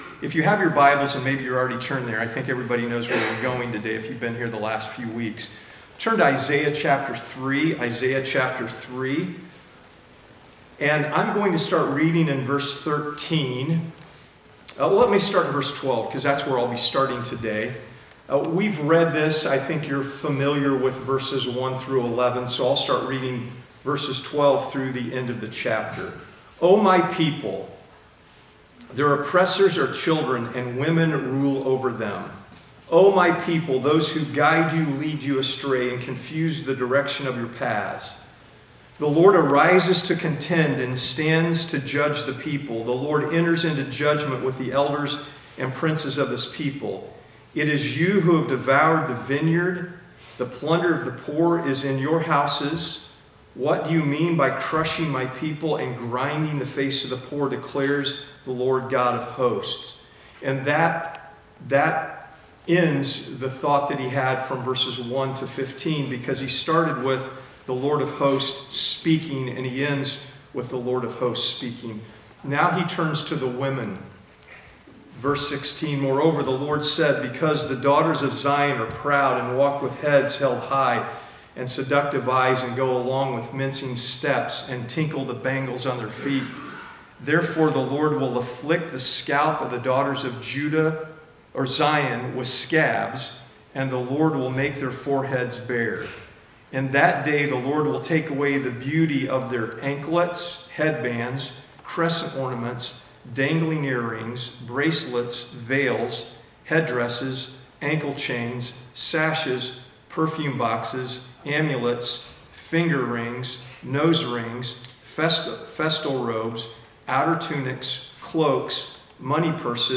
This is the third installment in a 3-part sermon series.